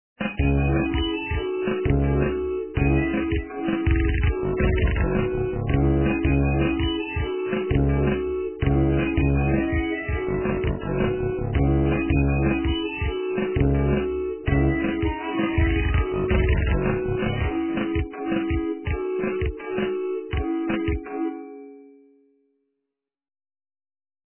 - русская эстрада